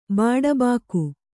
♪ bāḍa bāku